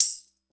Index of /90_sSampleCDs/Roland L-CD701/PRC_Latin 2/PRC_Tambourines
PRC TAMB S0C.wav